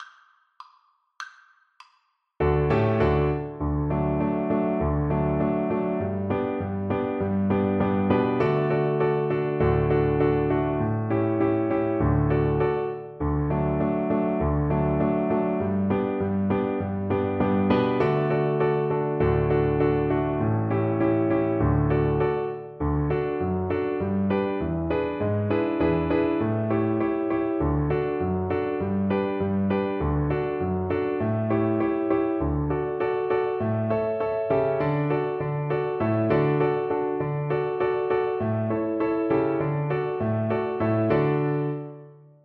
Violin
Allegro (View more music marked Allegro)
2/4 (View more 2/4 Music)
D5-F#6
D major (Sounding Pitch) (View more D major Music for Violin )
Traditional (View more Traditional Violin Music)